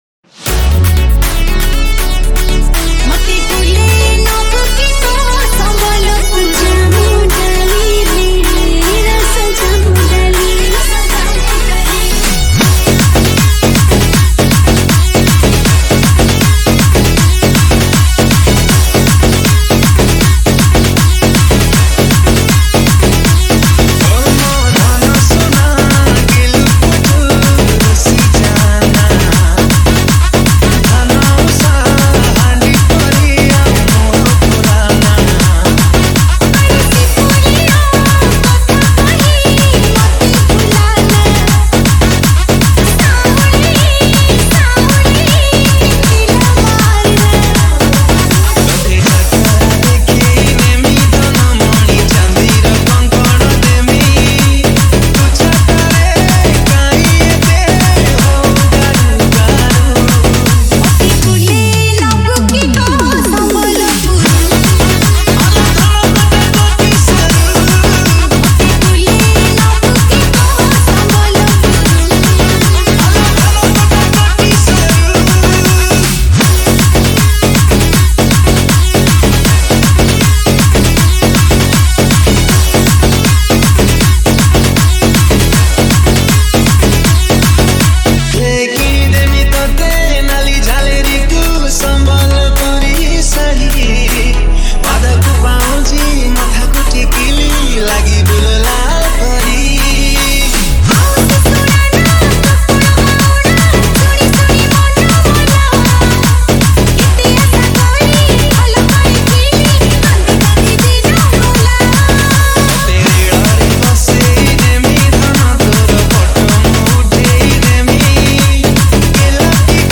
Category:  New Odia Dj Song 2023